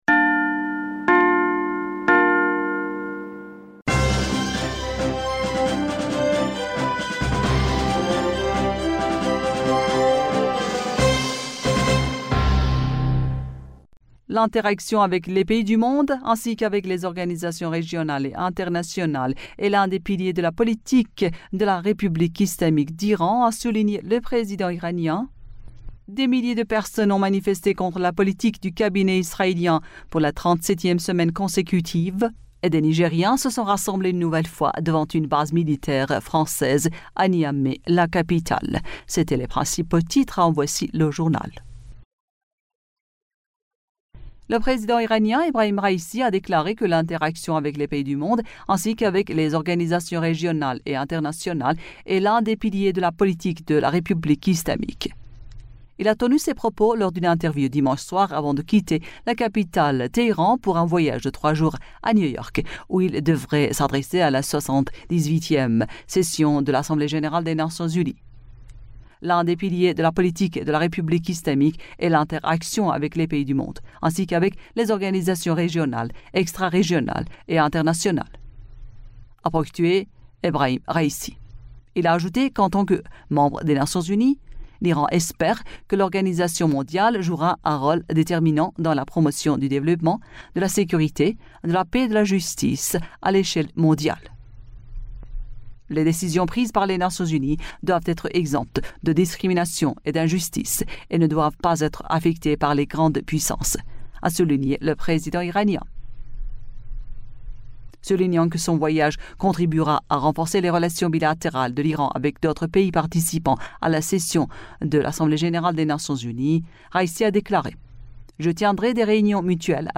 Bulletin d'information du 18 Septembre 2023